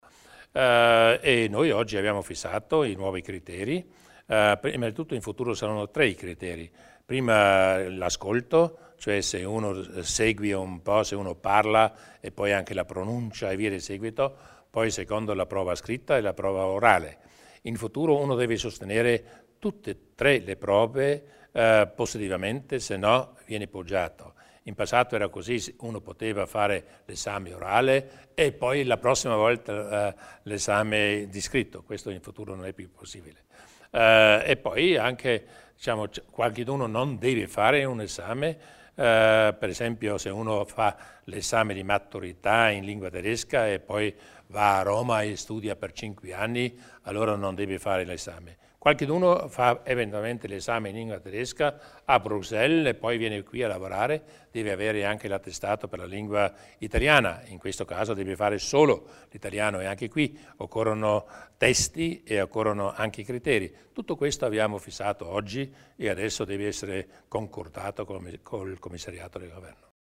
Il Presidente Durnwalder spiega le novità riguardanti l'esame di bilinguismo